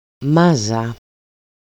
[ˈmaza] 'clump' See Modern Greek phonology